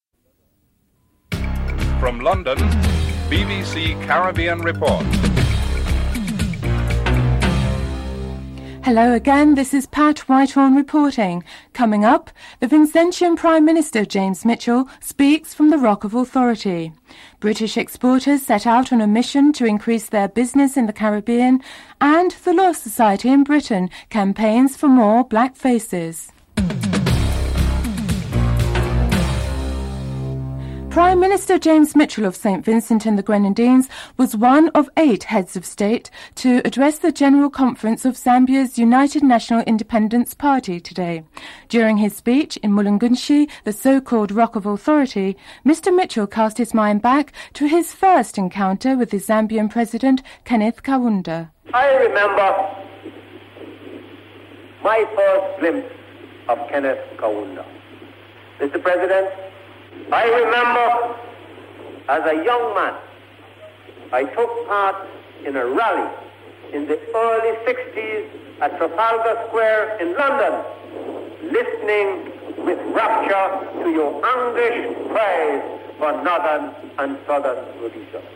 Loss of audio from min 05:05 onwards.
(Brief excerpt from the actual address included).
*Break in audio from 05:05 onwards.